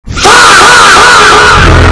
Faaaaaaaa Loud Asf Sound Button - Free Download & Play